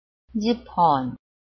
臺灣客語拼音學習網-客語聽讀拼-詔安腔-鼻尾韻
拼音查詢：【詔安腔】pon ~請點選不同聲調拼音聽聽看!(例字漢字部分屬參考性質)